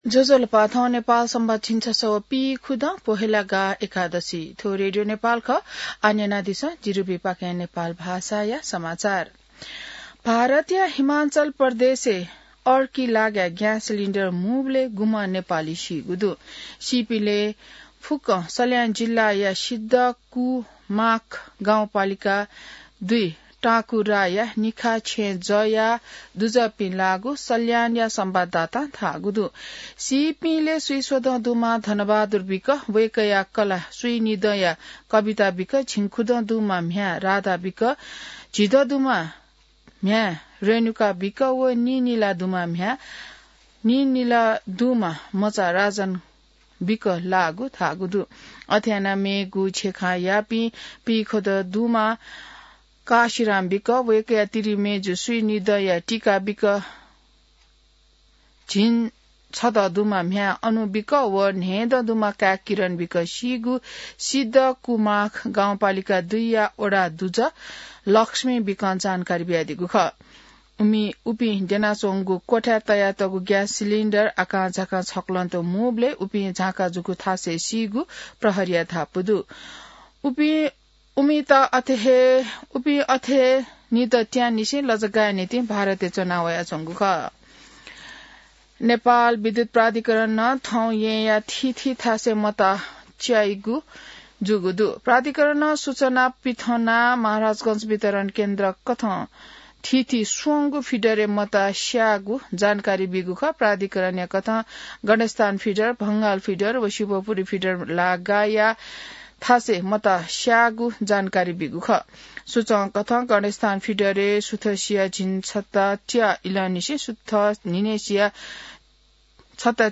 नेपाल भाषामा समाचार : ३० पुष , २०८२